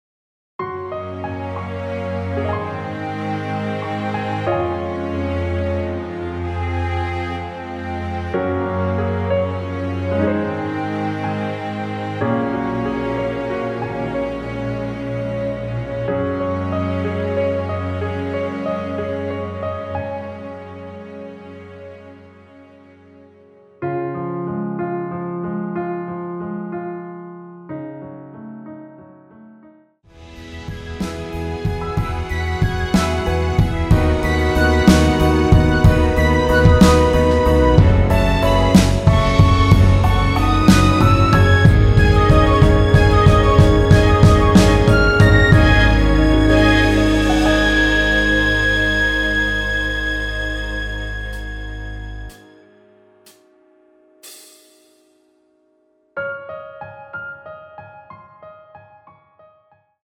3분 14초경 무반주 후 노래 들어가는 부분 박자 맞추기 쉽게 카운트 추가하여 놓았습니다.(미리듣기 확인)
Db
앞부분30초, 뒷부분30초씩 편집해서 올려 드리고 있습니다.